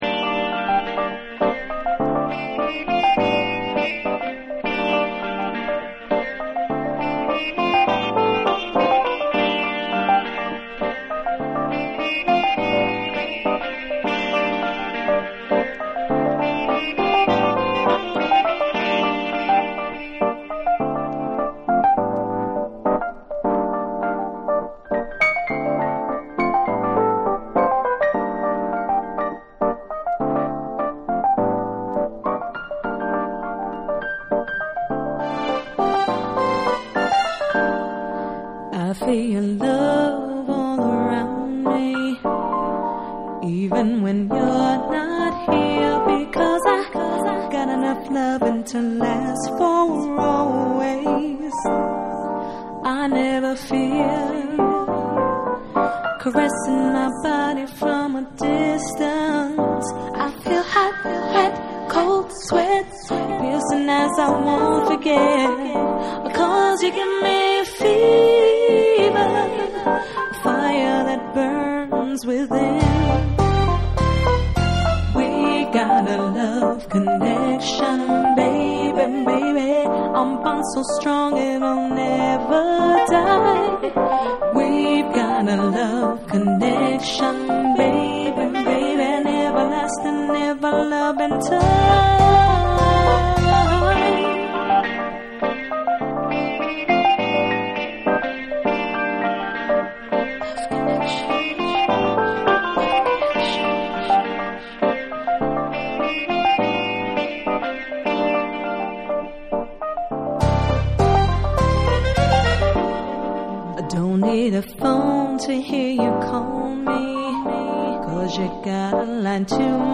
JAPANESE / TECHNO & HOUSE